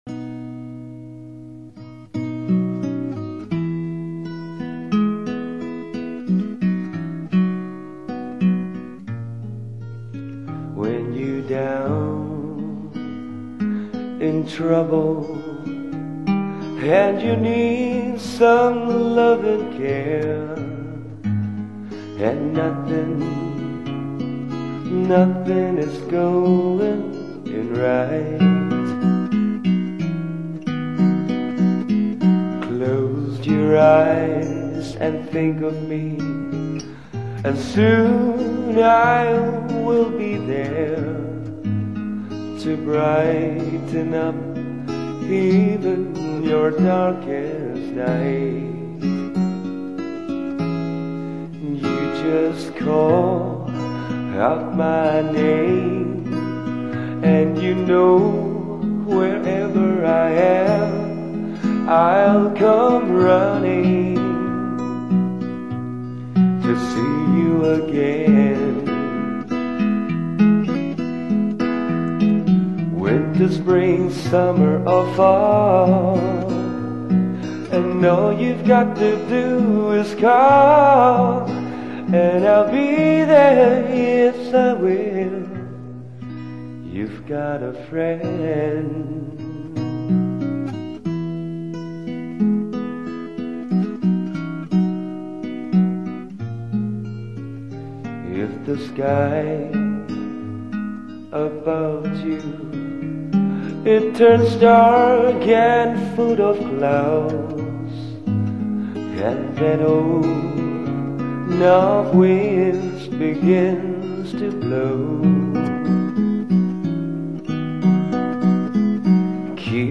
语    种：纯音乐
低吟浅唱，有如天籁，直触人心，荡埃涤尘。
[广告语] 乡村宁静雅致，民谣清新质朴。